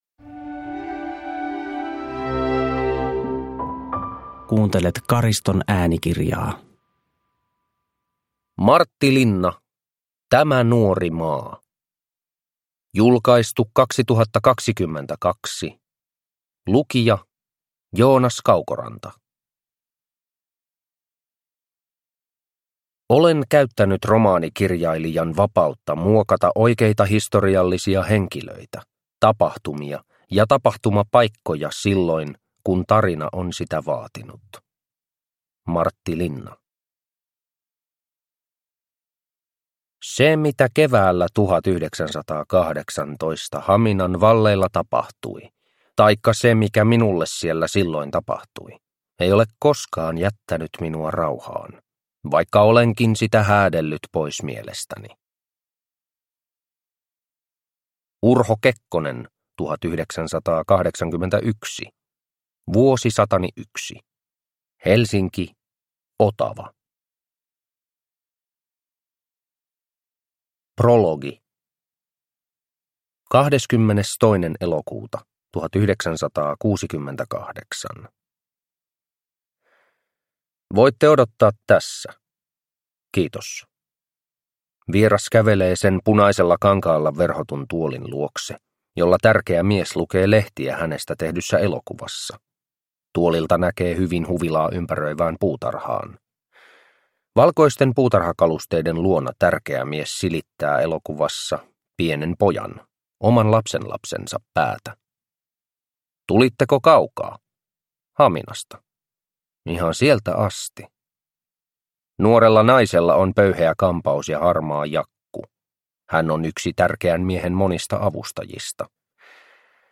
Tämä nuori maa – Ljudbok – Laddas ner